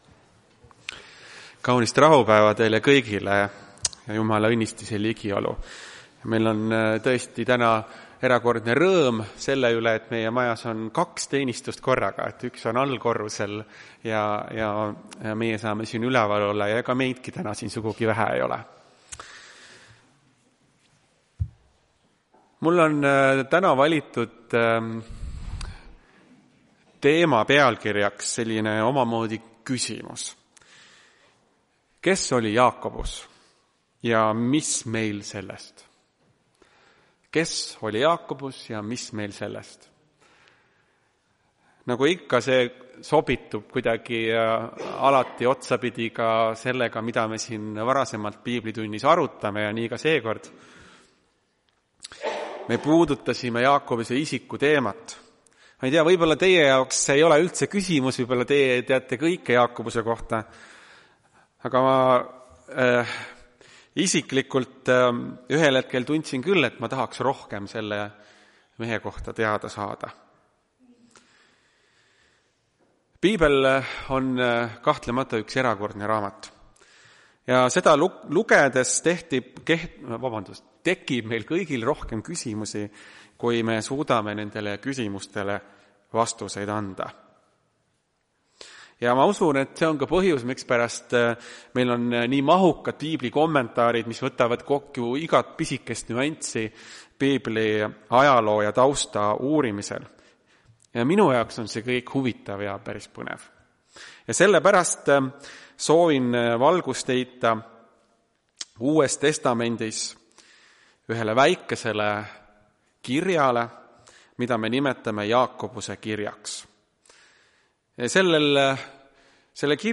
Tartu adventkoguduse 07.02.2026 teenistuse jutluse helisalvestis.